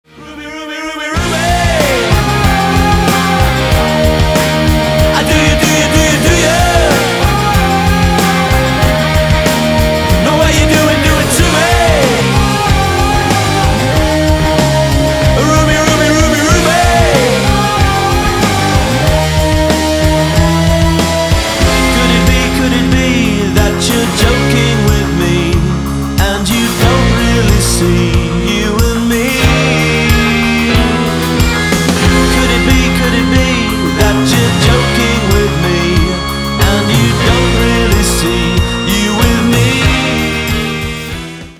• Качество: 320, Stereo
гитара
мужской вокал
инди рок
рок